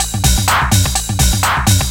DS 126-BPM A06.wav